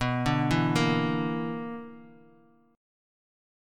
BmM7bb5 chord